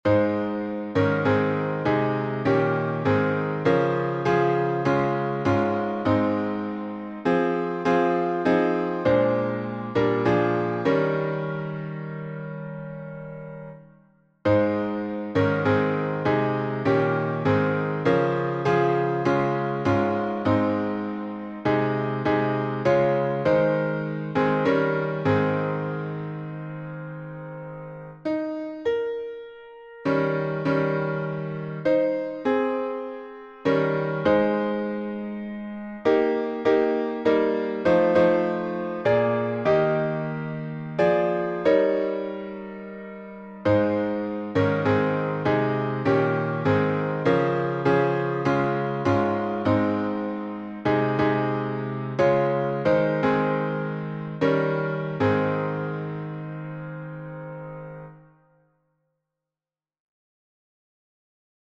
#3027: Softly and Tenderly — alternate harmonies | Mobile Hymns
Key signature: G major (1 sharp) Time signature: 6/8